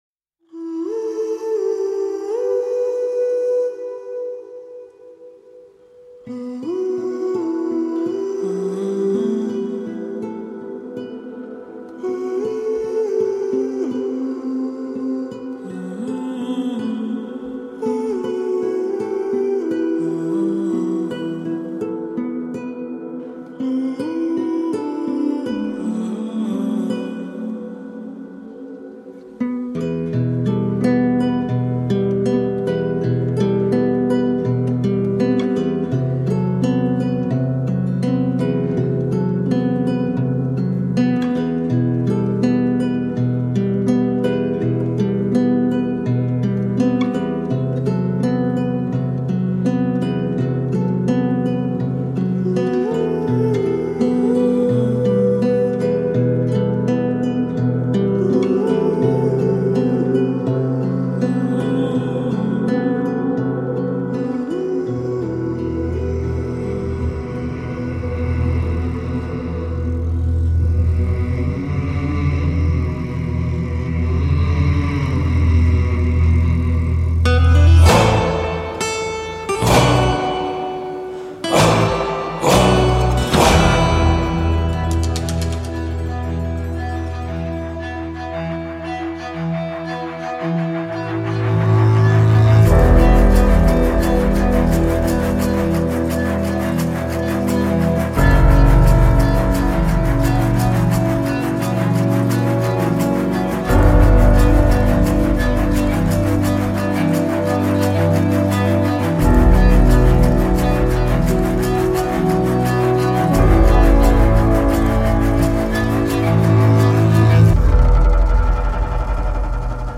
Guitares, vocalises, piano, cordes, percussions, nappes…